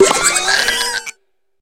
Cri de Farigiraf dans Pokémon HOME.